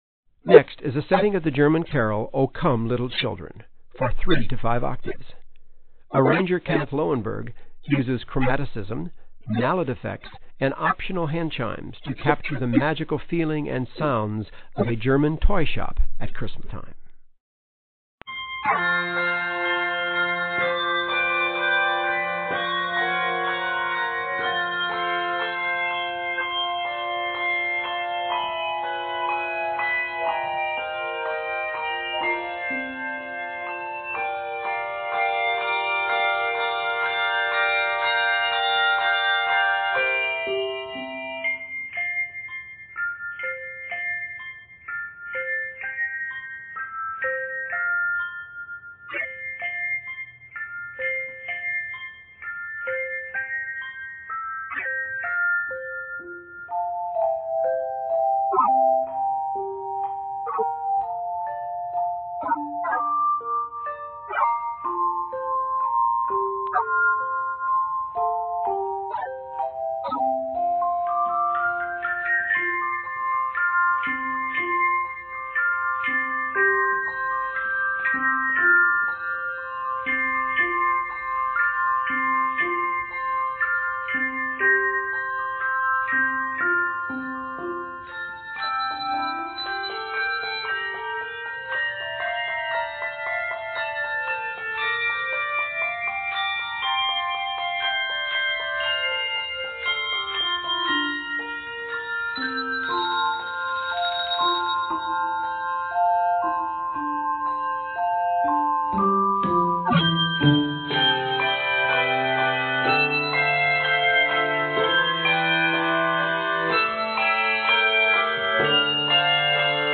German carol